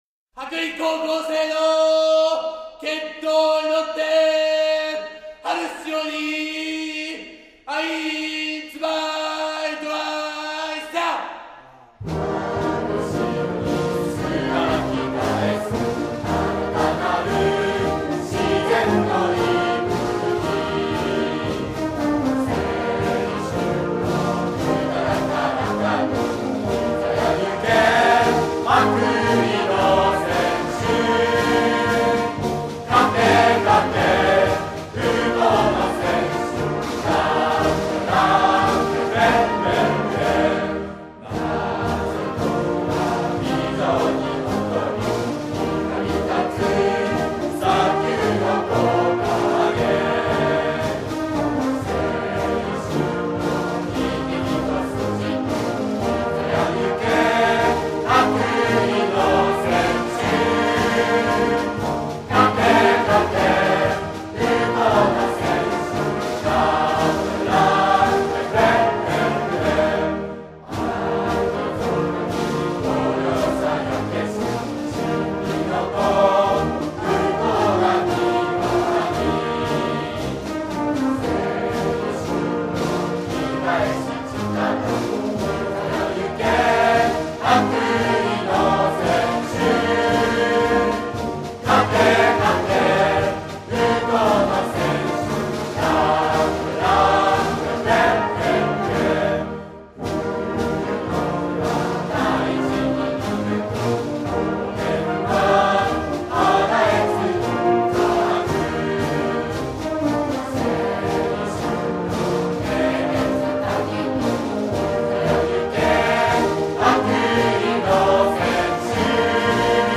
羽咋高校応援歌